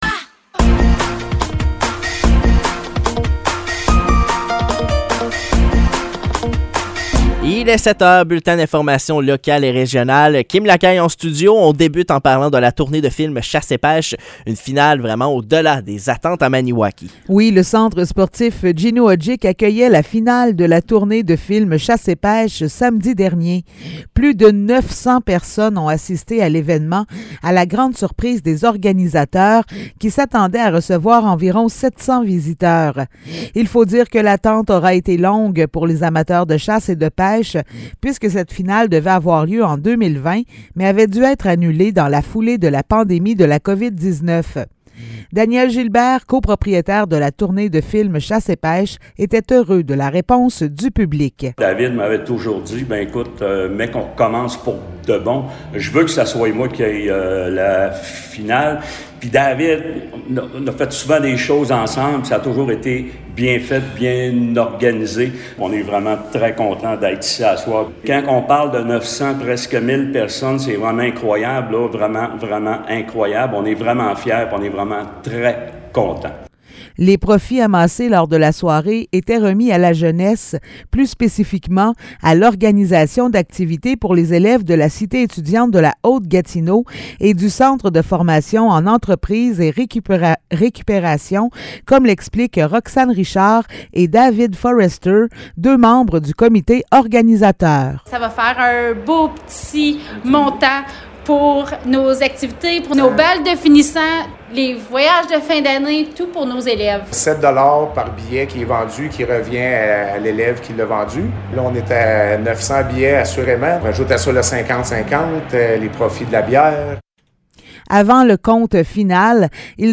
Nouvelles locales - 24 avril 2023 - 7 h